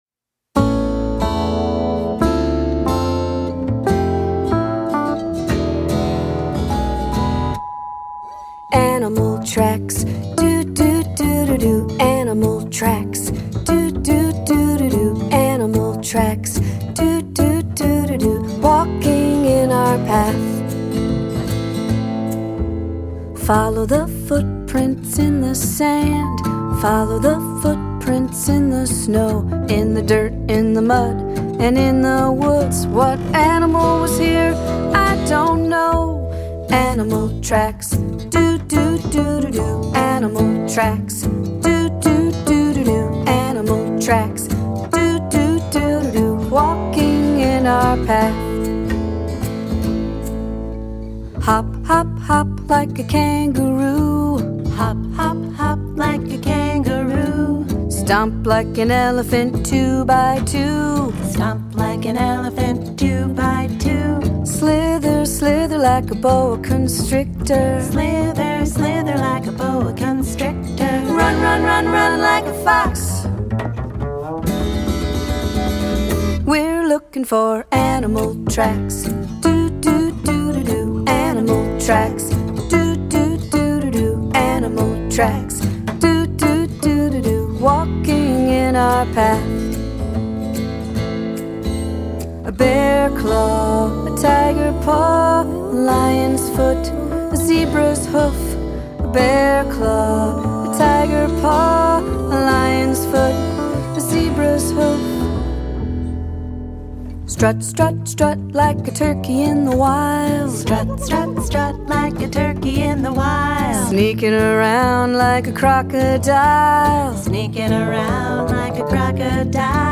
cozy, singable songs
acoustic folk punctuated by a bouncy ukulele